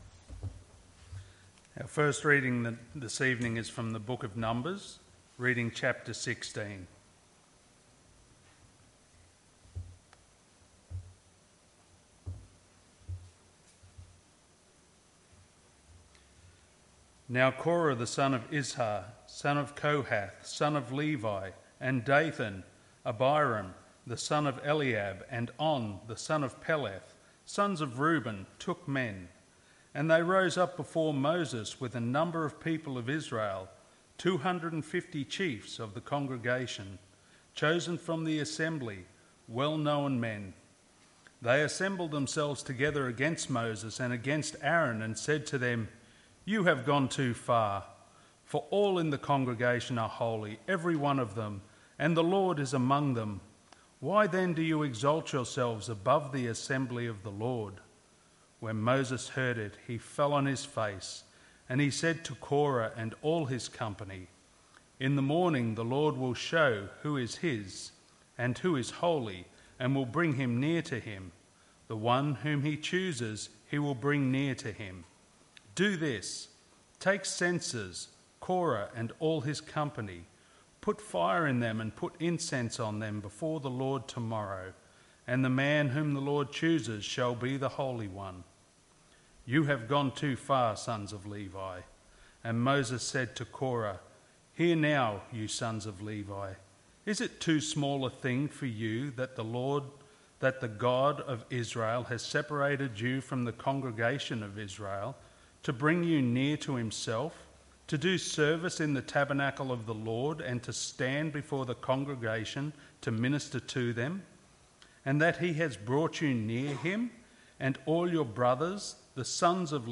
MP3 SUBSCRIBE on iTunes(Podcast) Notes 9 April 2023 - Evening Service Numbers 16:1-50 1 Corinthians 10:1-11